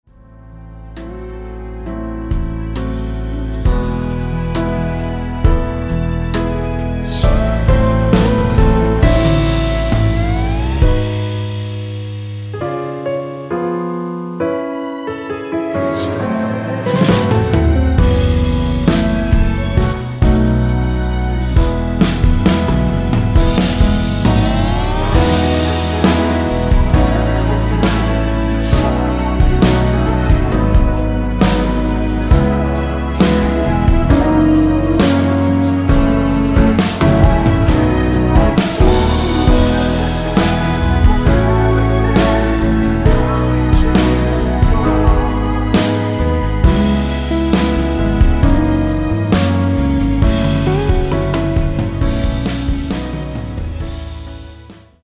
원키에서 (-3)내린 코러스 포함된 MR입니다.(미리듣기 확인)
앞부분30초, 뒷부분30초씩 편집해서 올려 드리고 있습니다.
중간에 음이 끈어지고 다시 나오는 이유는